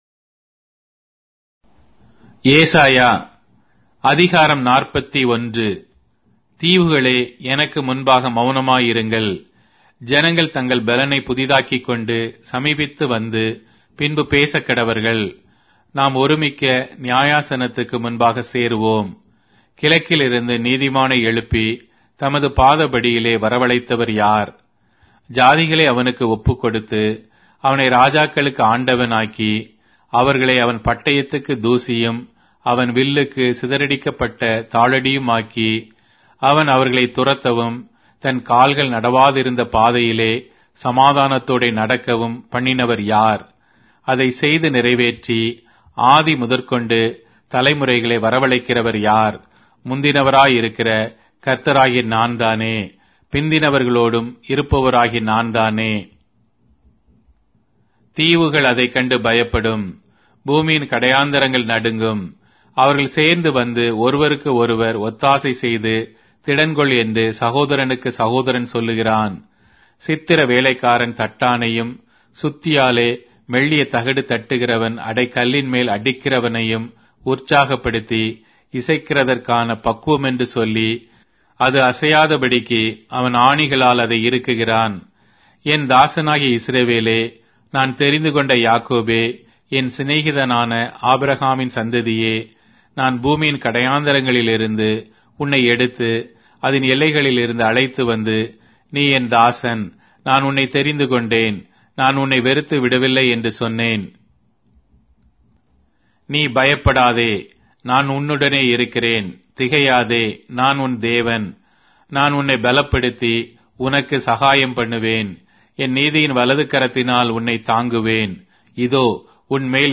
Tamil Audio Bible - Isaiah 22 in Ervte bible version